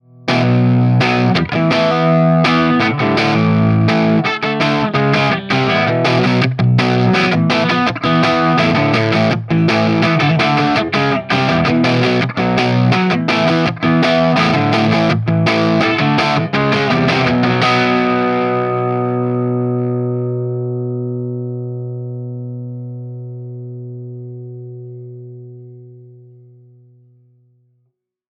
18 Watt v6 - EL84 Dirty Marshall Greenbacks
Note: We recorded dirty 18W tones using both the EL84 and 6V6 output tubes.
18W_DIRTY_EL84_MarshallGreenbacks.mp3